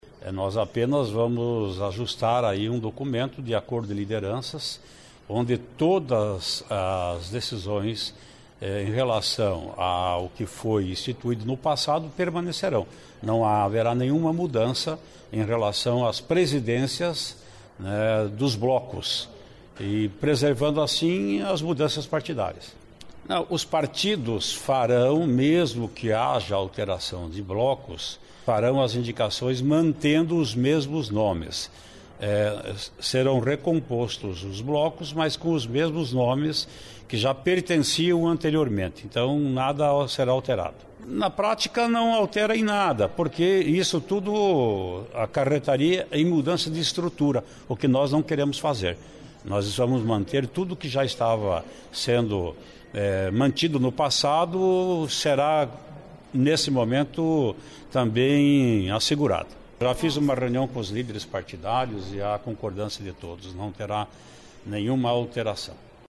Em entrevista coletiva, no começo da tarde desta segunda-feira (23), o rpesidente da Assembleia Legislativa, Ademar traiano (PSDB) explicou aos jornalistas que, mesmo ocorrendo mudanças de nomes nos partidos que compõem as bancadas, houve um acordo  com os parlamentares para que as lideranças permaneçam as mesmas.